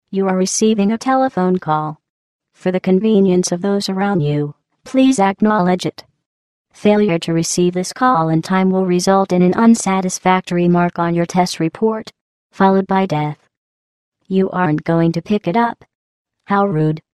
Glados Incoming Call Sound Effect Free Download